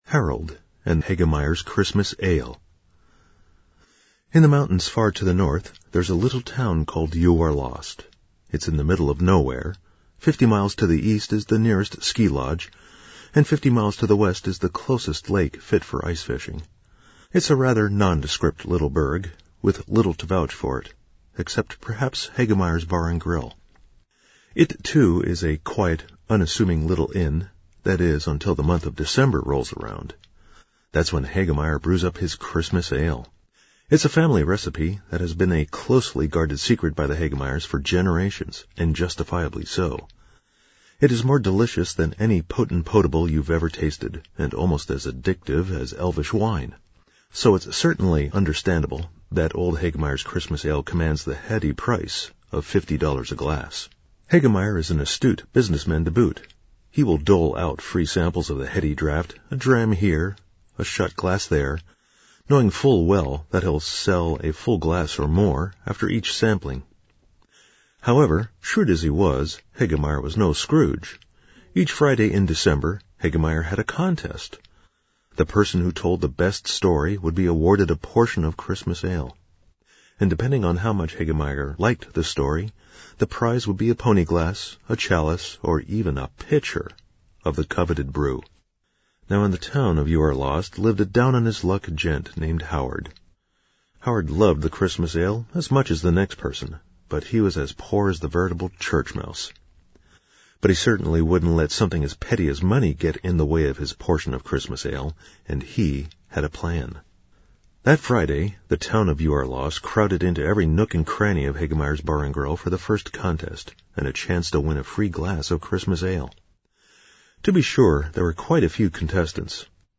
Christmas Ale   A real pun-isher from LVSG's Dec 12, 2018 Story Circle.